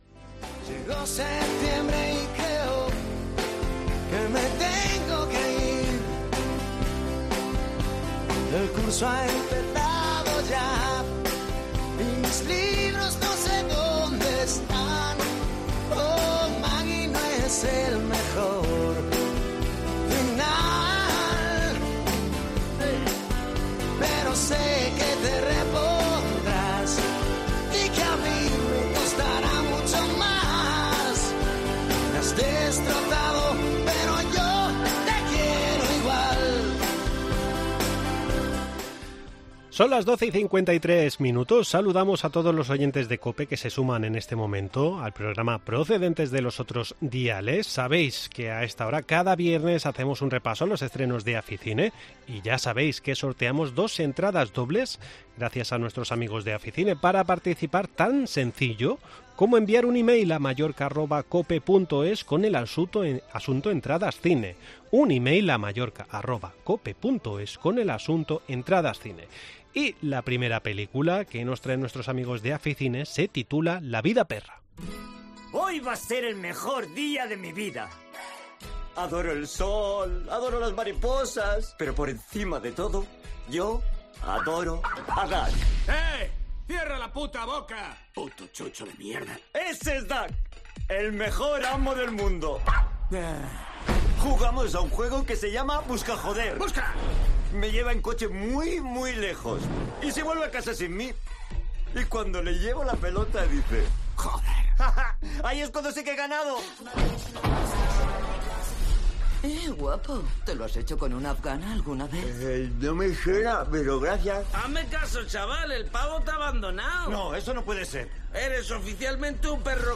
Entrevista en La Mañana en COPE Más Mallorca, viernes 22 septiembre de 2023.